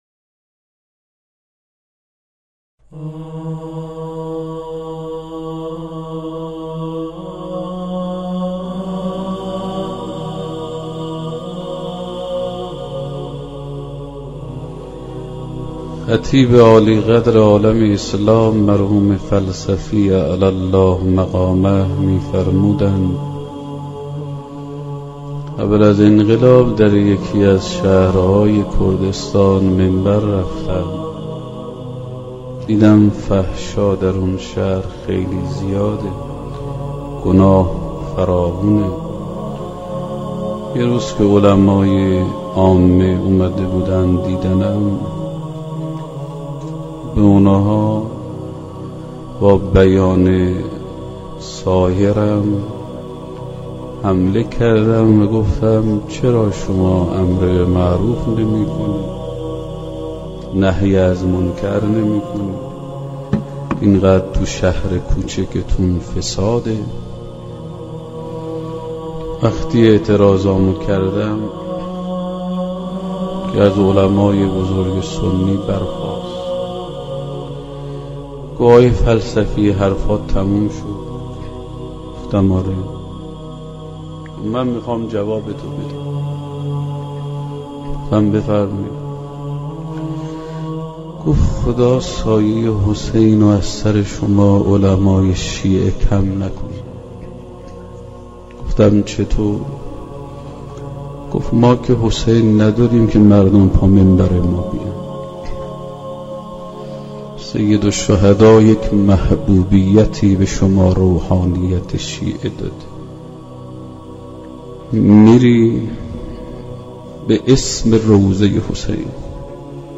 سخنران: حجت‌الاسلام‌والمسلمین